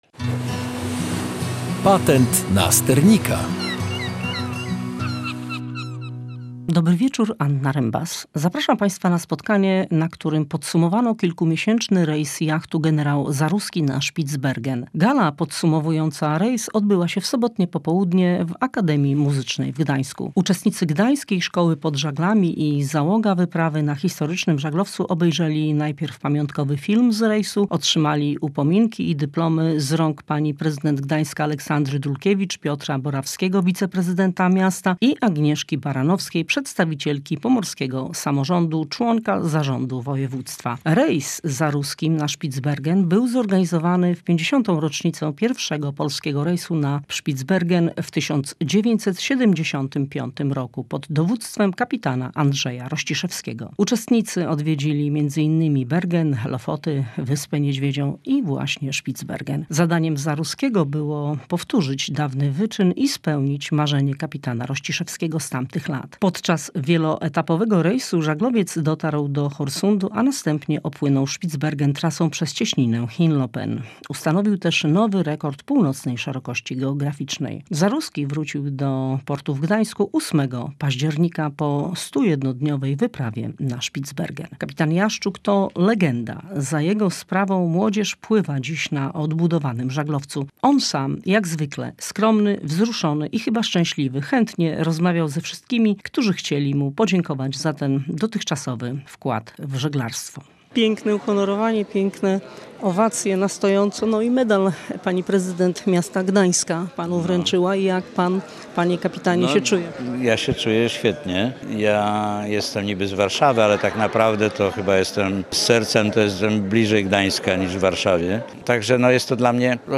podczas uroczystej gali podsumowującej rejs na Spitsbergen żaglowca Generała Zaruskiego